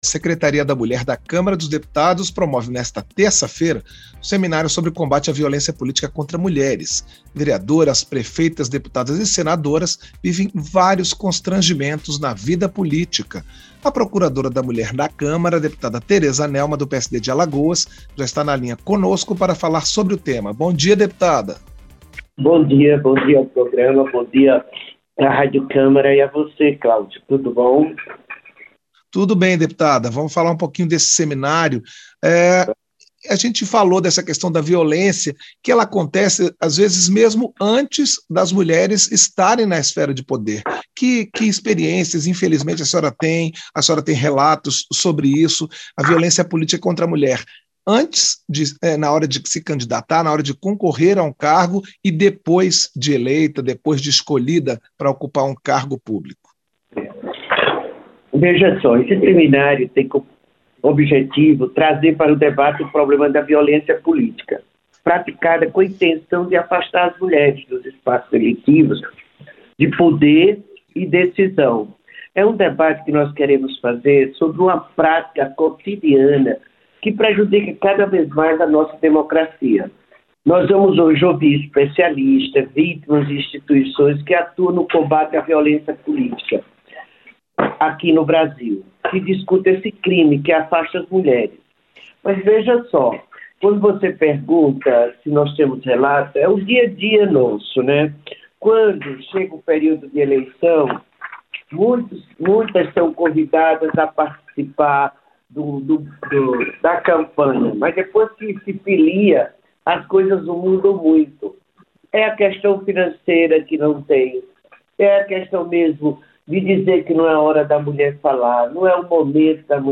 • Entrevista - Dep. Tereza Nelma (PSD-AL)
Programa ao vivo com reportagens, entrevistas sobre temas relacionados à Câmara dos Deputados, e o que vai ser destaque durante a semana.